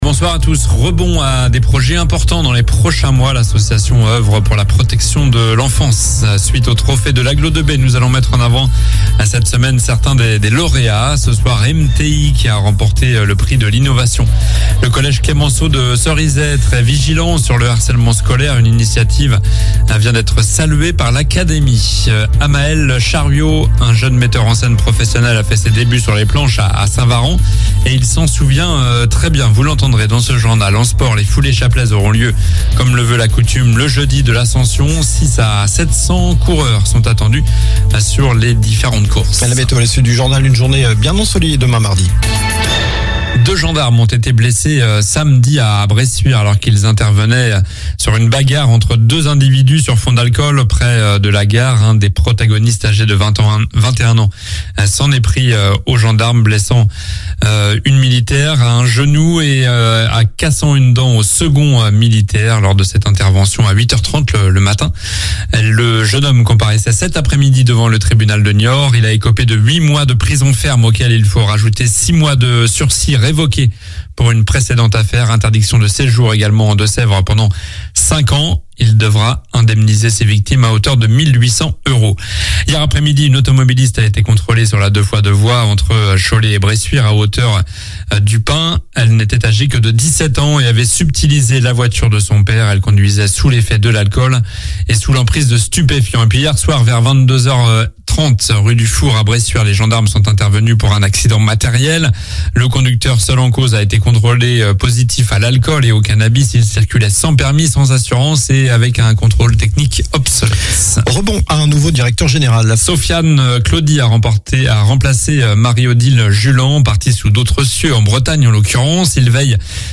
Journal du lundi 15 mai (soir)